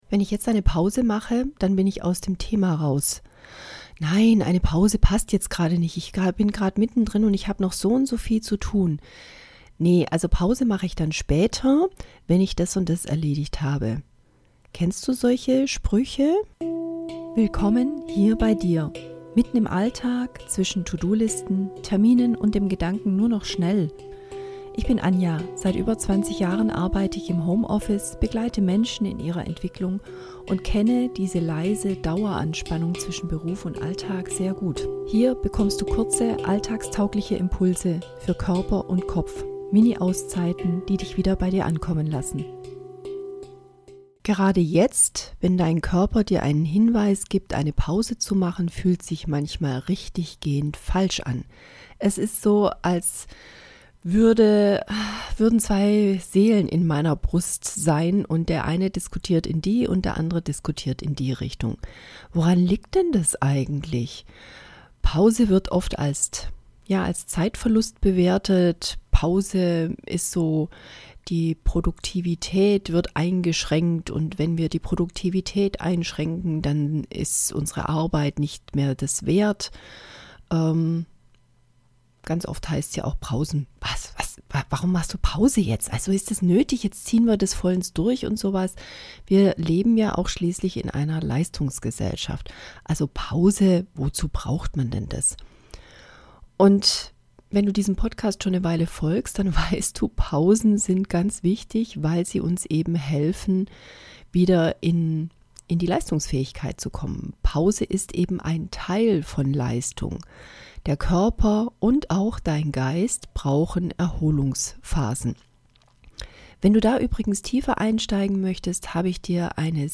Am Ende der Folge begleite ich dich durch eine kurze angeleitete Pause – einen kleinen Raum zum Durchatmen, Loslassen und Neuauftanken mitten im Tag.